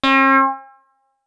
criminal - synth.wav